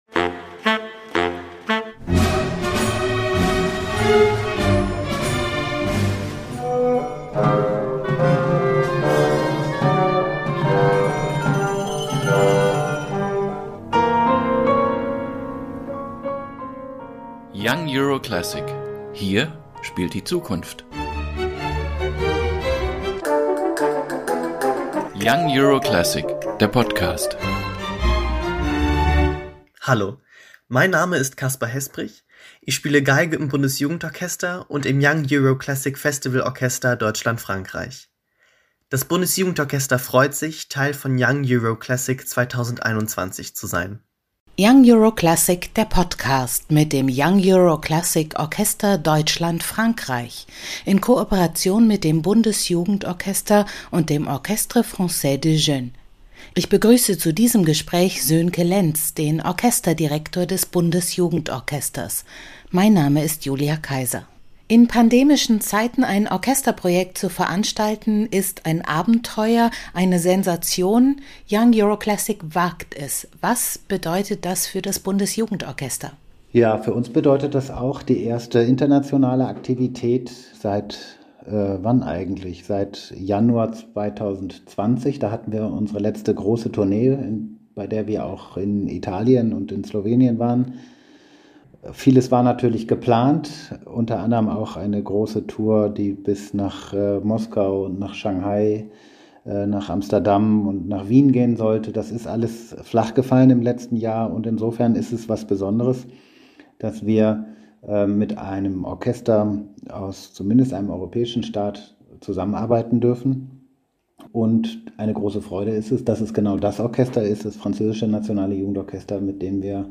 Ein Gespräch über das, was die Beteiligten verbindet, was sie unterscheidet – und was Mozarts große Reisen mit solch intensiven Austauschprojekten zu tun haben.